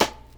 Trigga Man Snare.wav